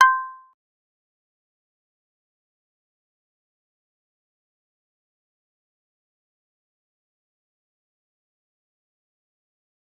G_Kalimba-C6-pp.wav